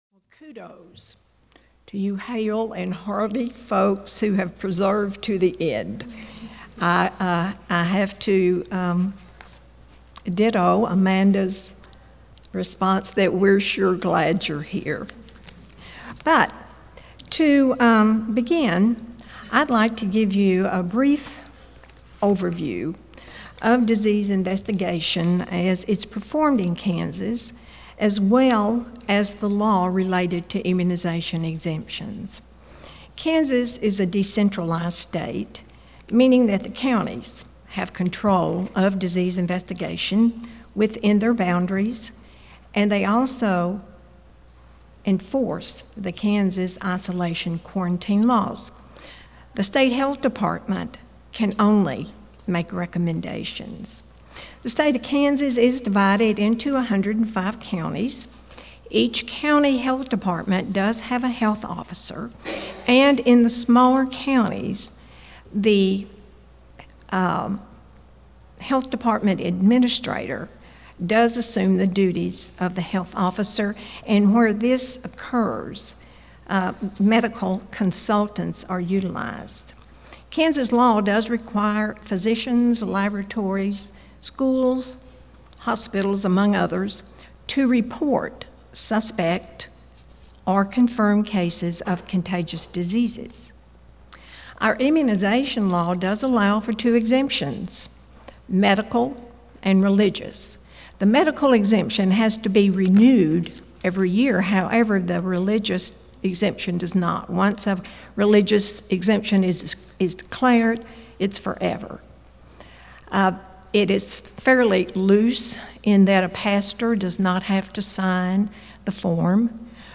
MPH Audio File Slides Recorded presentation